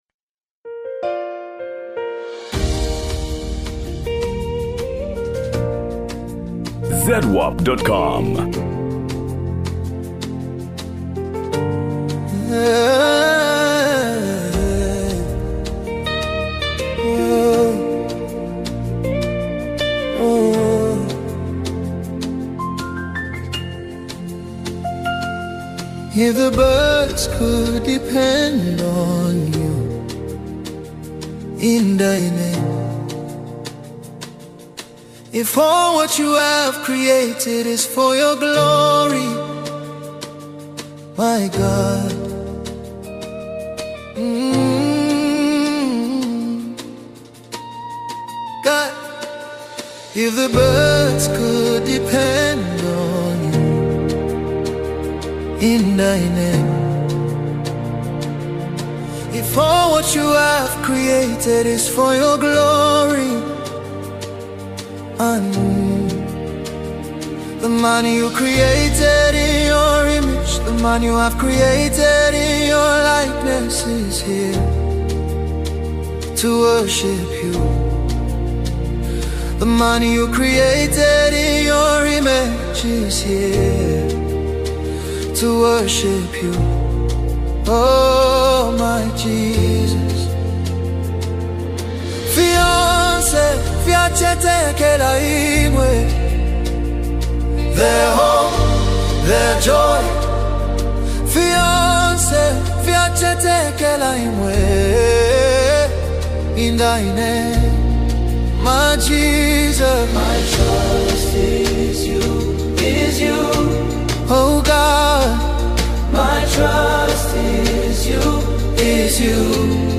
Género musical: Gospel